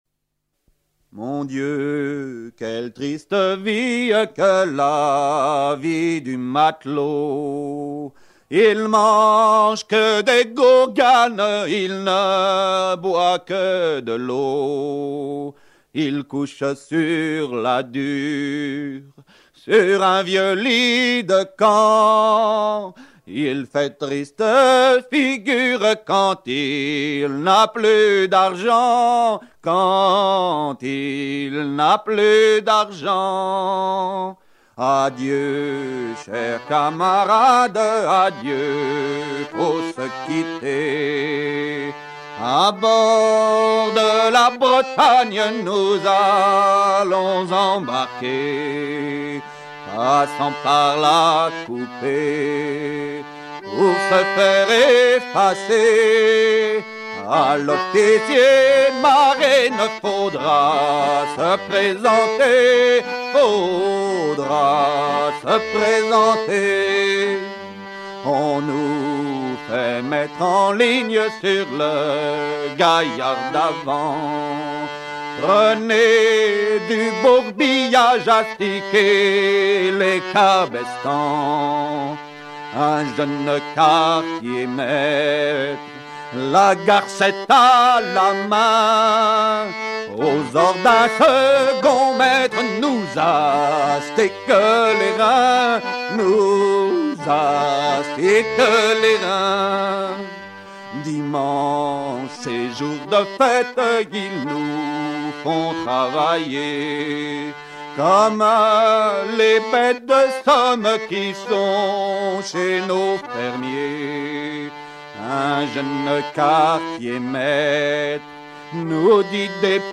Région ou province Normandie
Genre strophique